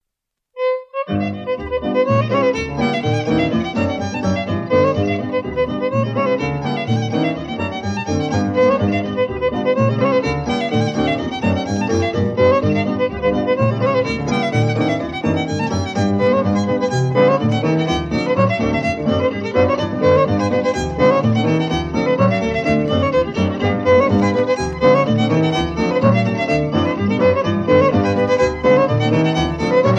Patter (two instrumentals)